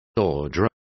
Complete with pronunciation of the translation of ordure.